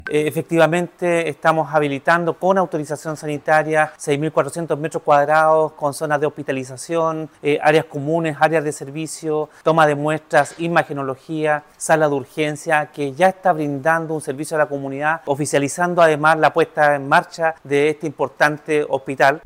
Durante el recorrido, el seremi cuyul detalló los alcances técnicos de la habilitación de este recinto de salud, indicando que “”estamos habiltando, con autorización sanitaria, 6.400 metros cuadrados con zonas de hospitalización, áreas comunes, áreas de servicio, toma de muestras, imagenología y sala de urgencias”.